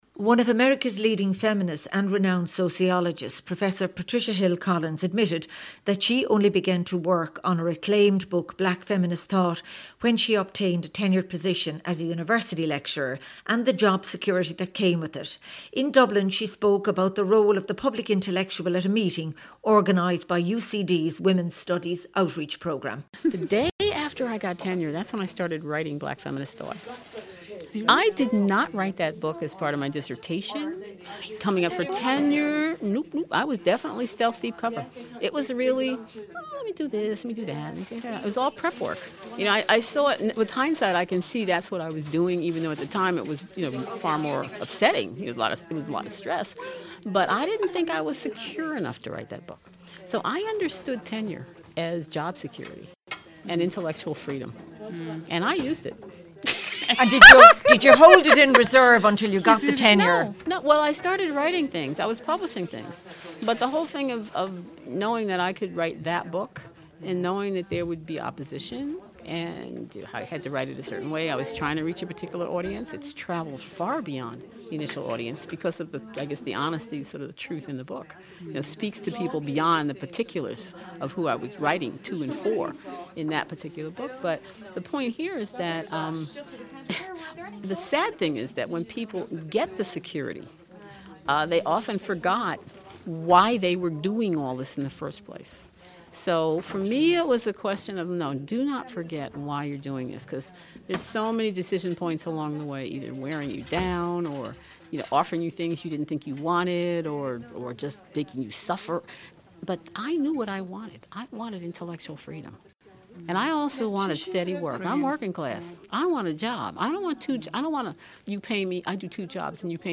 In Dublin she spoke about the role of the public intellectual at a meeting organised by University College Dublin Women’s Studies Outreach programme.